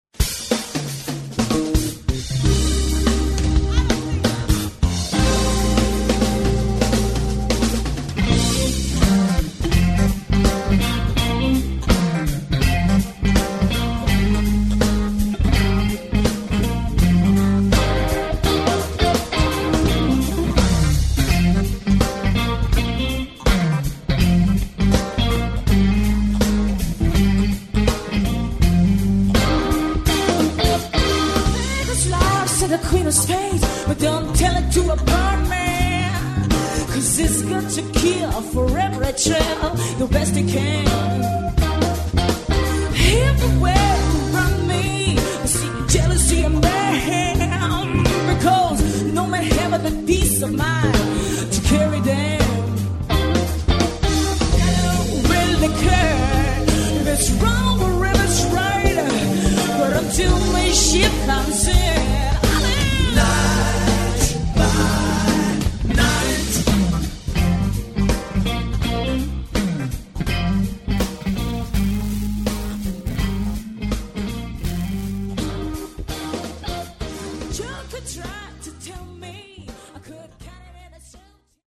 Live at the Melkweg, Amsterdam, Netherlands